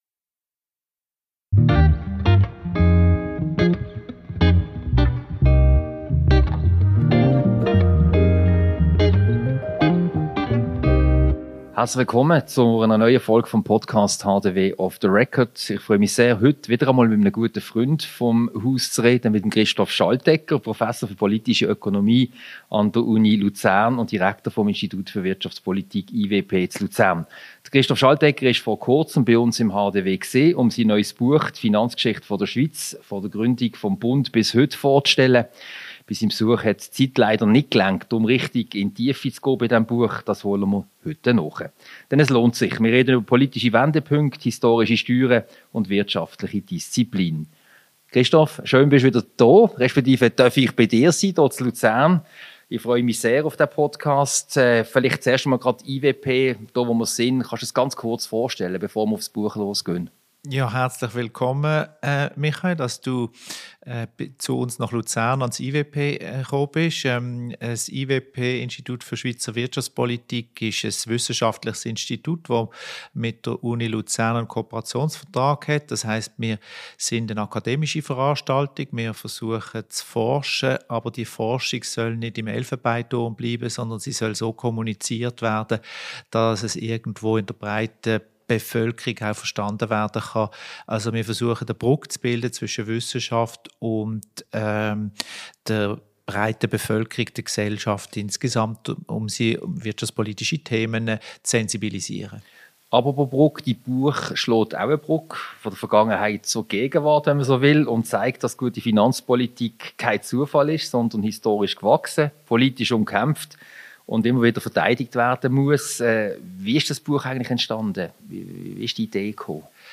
Ein Gespräch über die Finanzgeschichte der Schweiz, deren politischen Wendepunkte, über historische Steuern und die wirtschaftliche Disziplin. Diese Podcast-Ausgabe wurde am Institut für Wirtschaftspolitik in Luzern aufgezeichnet.